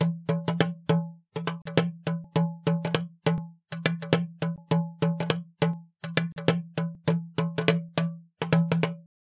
手鼓和无公鸡
Tag: 102 bpm Pop Loops Percussion Loops 1.59 MB wav Key : Unknown Cubase